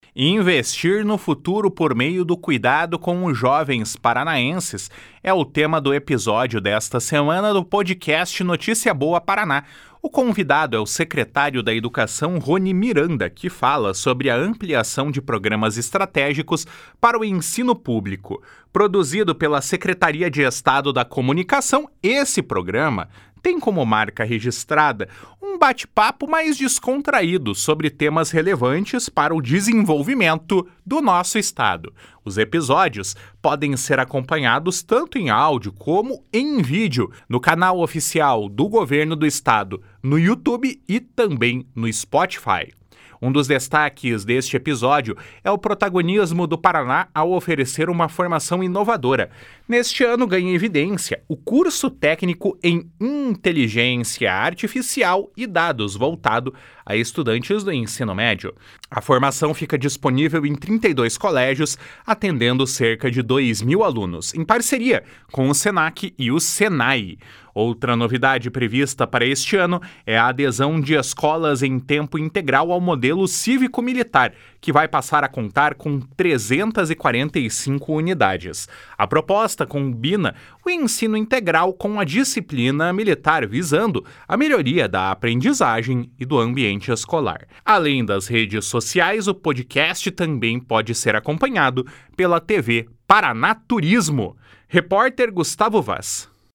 Investir no futuro por meio do cuidado com os jovens paranaenses é o tema do episódio desta semana do podcast Notícia Boa Paraná. O convidado é o secretário da Educação, Roni Miranda, que fala sobre a ampliação de programas estratégicos para o ensino público. Produzido pela Secretaria de Estado da Comunicação, o programa tem como marca registrada um bate-papo descontraído sobre temas relevantes para o desenvolvimento do Estado.